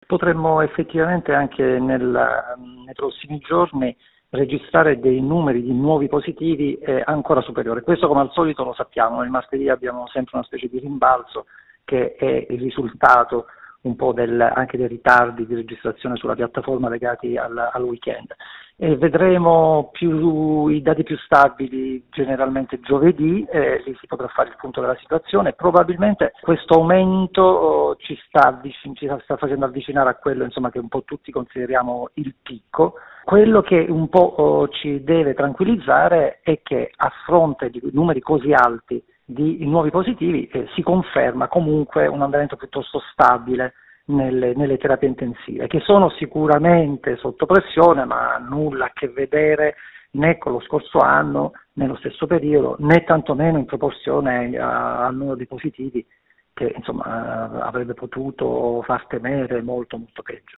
A fronte però di numeri così alti nelle infezioni, al momento le terapie intensive stanno registrando solo un lieve aumento quotidiano e la curva è pressoché stabile. Questo deve rassicurarci, dice ai nostri microfoni l’epidemiologo Pierluigi Lopalco: